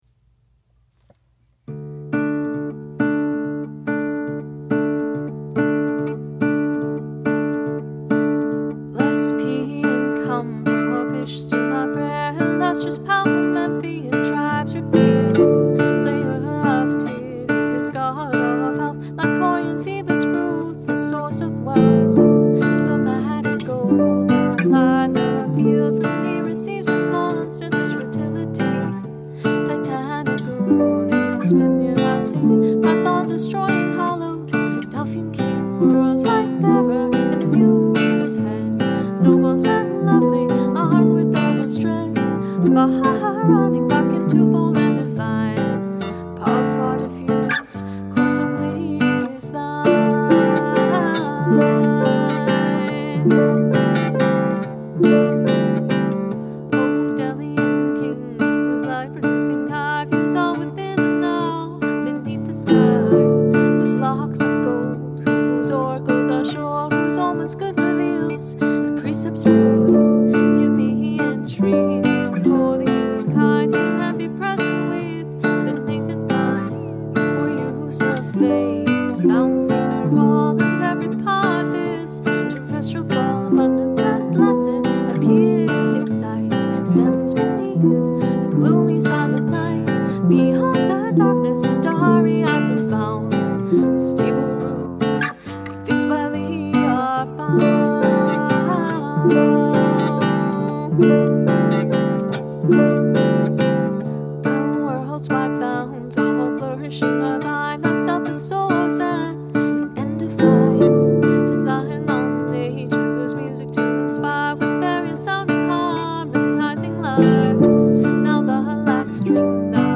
singing softly in background so you can practice karaoke